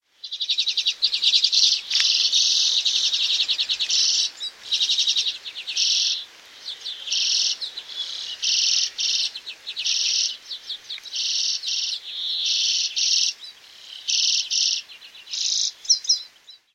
urpiainen-copyright-birdlife.mp3